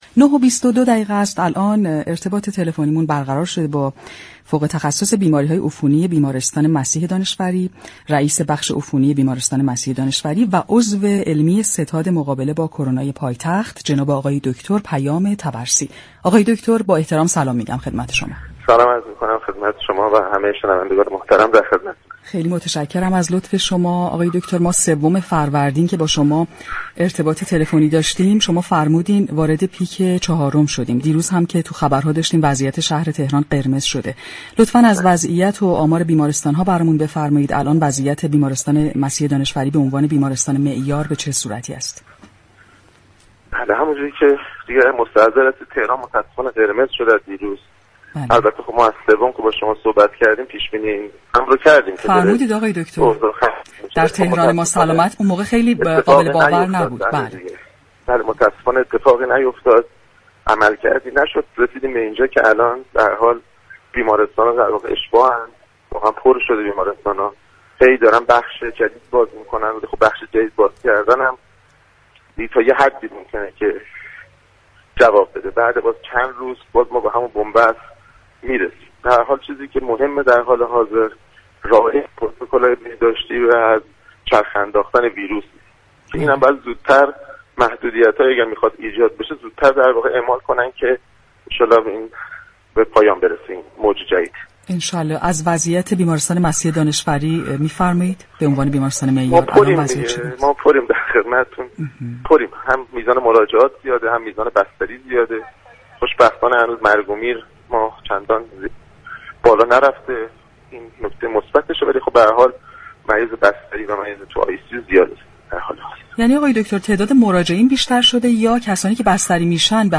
در گفتگو با برنامه «تهران ما سلامت» رادیو تهران